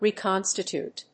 /rìːkάnstət(j)ùːt(米国英語), rìːkάkˈɔnstətjùːt(英国英語)/